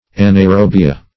Anaerobia \An*a`["e]r*o"bi*a\, Anaerobes \An"aer*obes\,